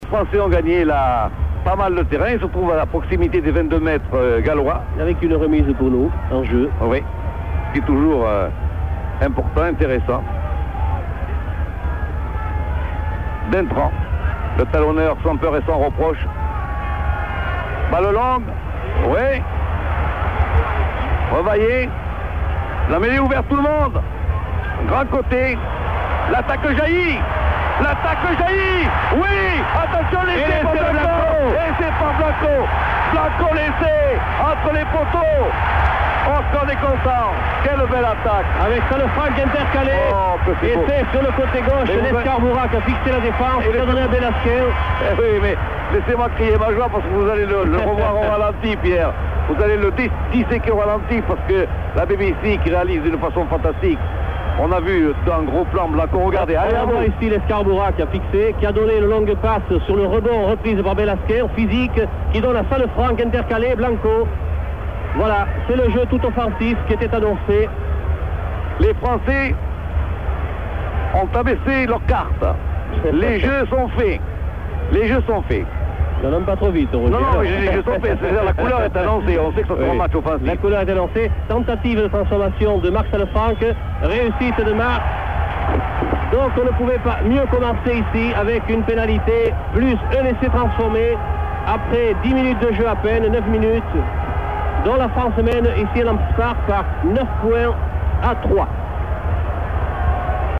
,,,AUDIO - Commentaire de Roger Couderc et Pierre Albaladejo: